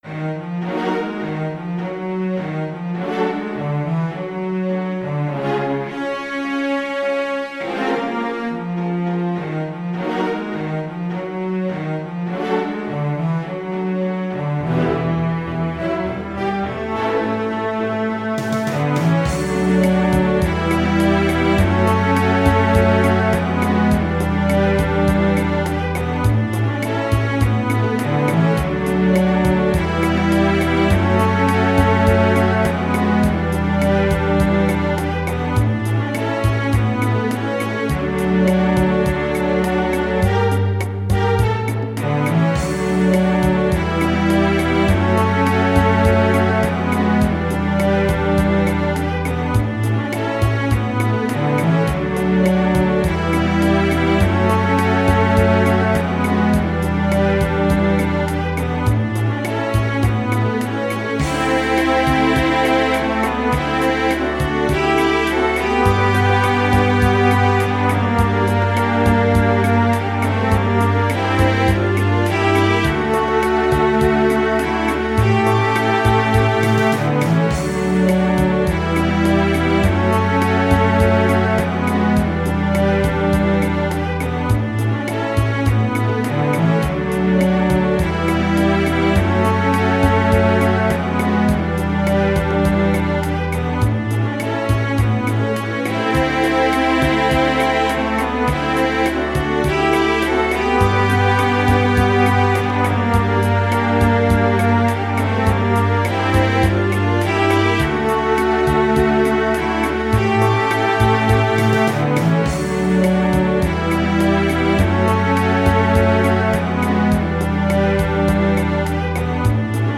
Cello Double Bass Tympani
Drums Percussion Tuned Percussion
Piano Harpsichord Hammond Organ
Synthesizer Classical Guitar Electric Guitar